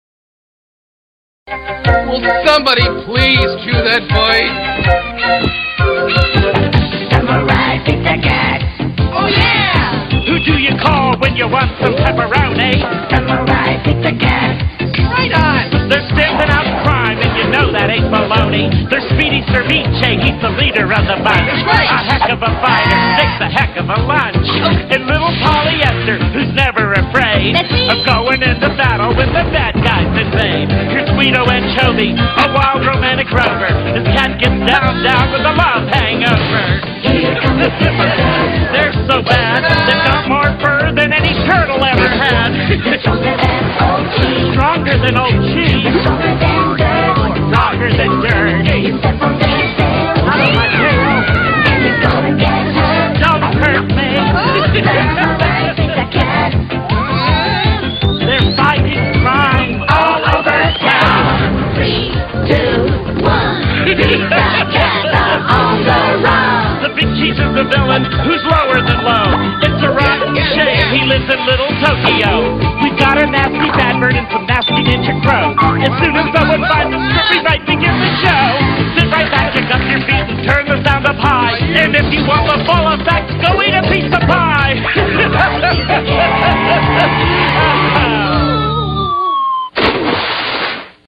BPM160
Audio QualityCut From Video